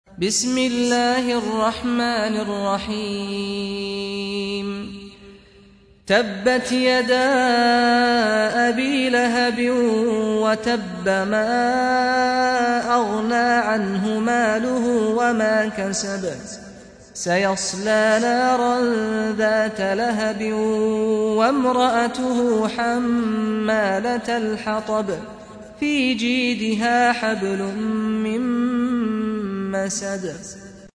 سورة المسد | القارئ سعد الغامدي